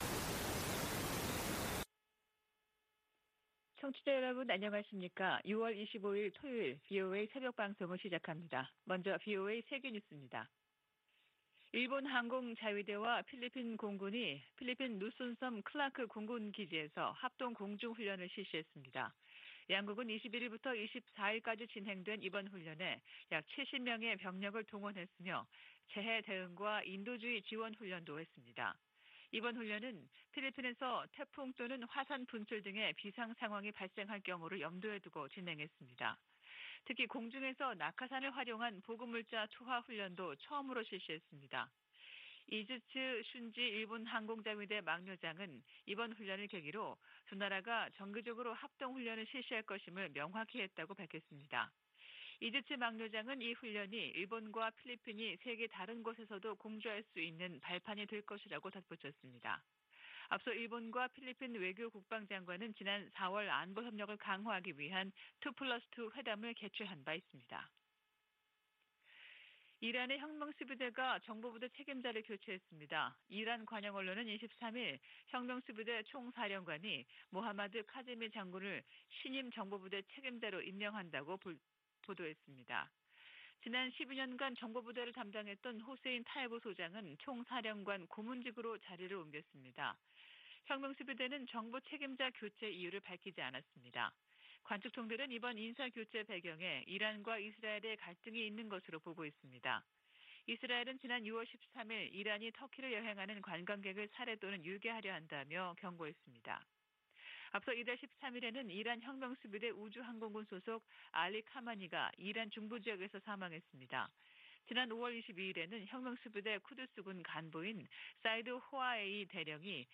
세계 뉴스와 함께 미국의 모든 것을 소개하는 '생방송 여기는 워싱턴입니다', 2022년 6월 25일 아침 방송입니다. '지구촌 오늘'에서는 유럽연합(EU) 정상회의에서 우크라이나의 회원 후보국 지위를 확정한 이야기, '아메리카 나우'에서는 대법원이 공공장소에서 권총 소지를 허용한 소식 전해드립니다.